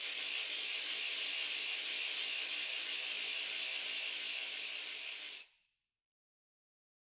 Ratchet1-Fast_v1_rr1_Sum.wav